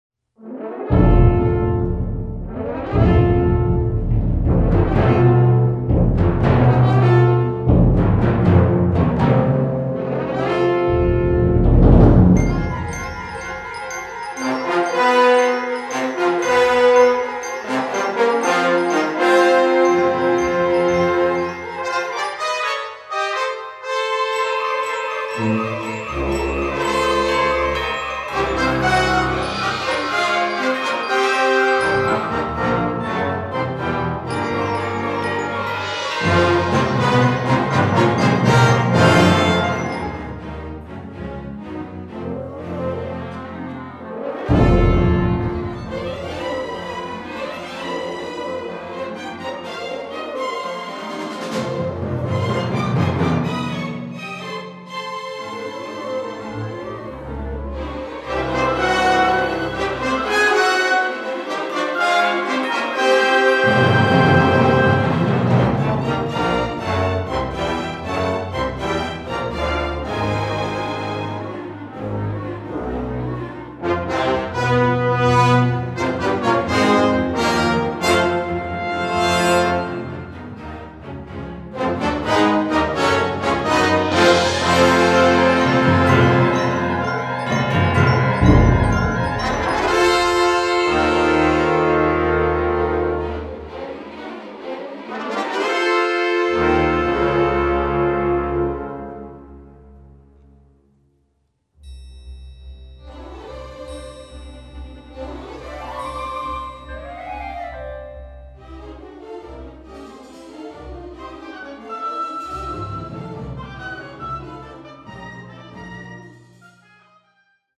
Five Orchestral Works Inspired by New Mexico